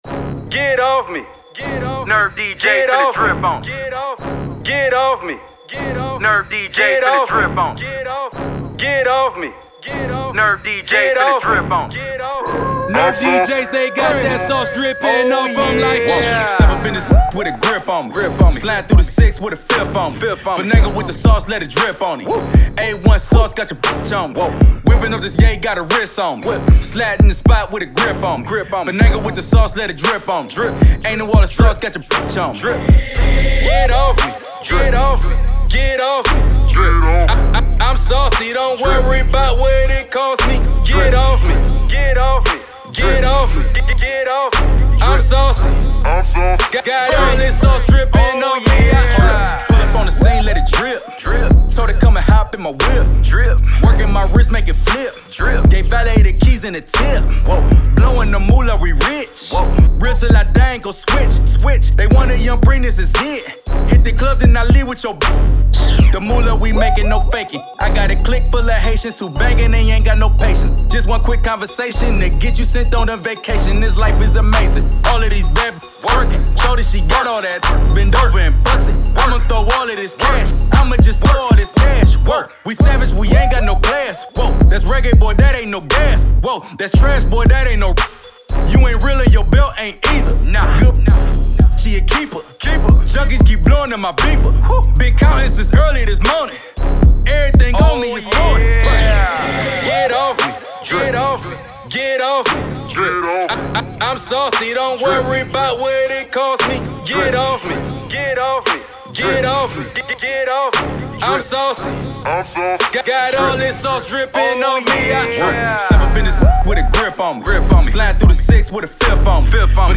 Club Banger